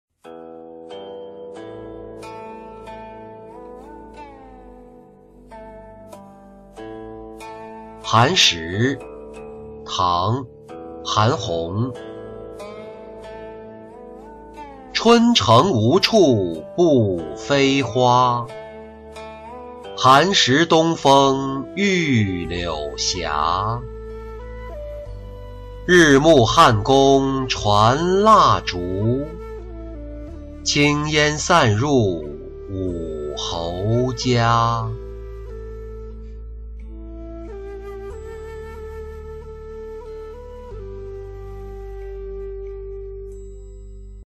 寒食-音频朗读